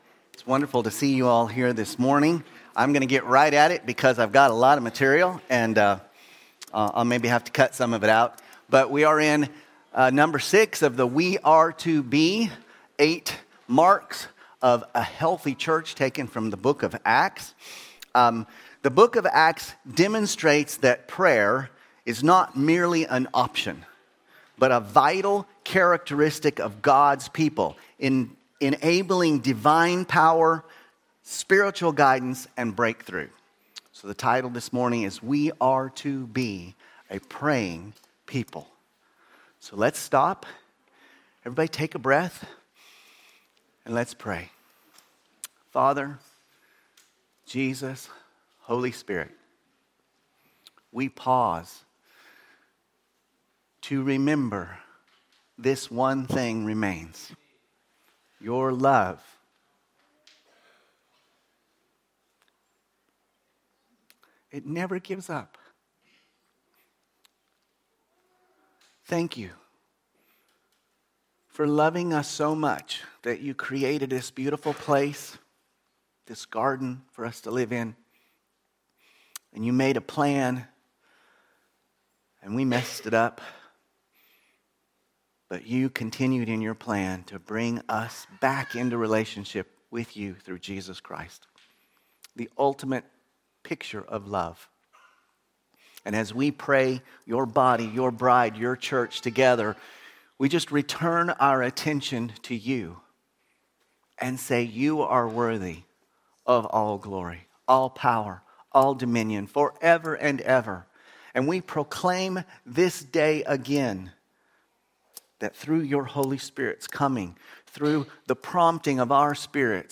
From Series: "Sunday Service"